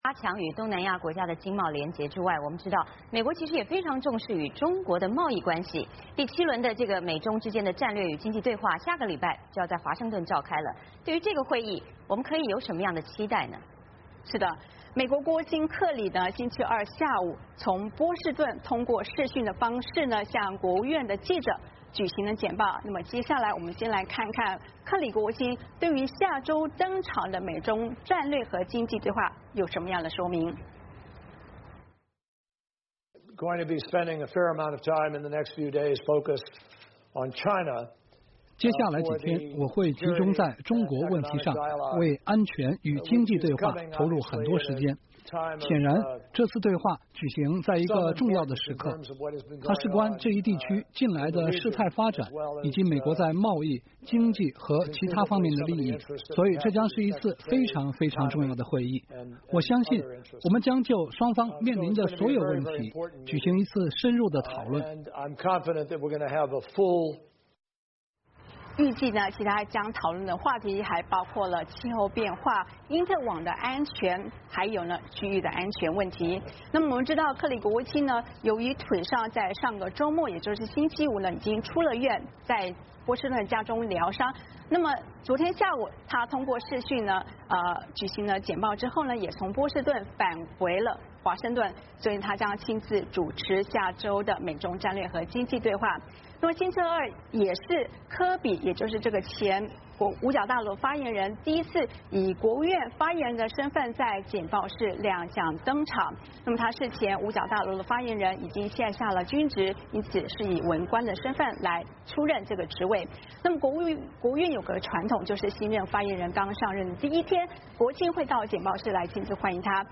VOA连线：克里国务卿返回华盛顿 新发言人柯比正式登场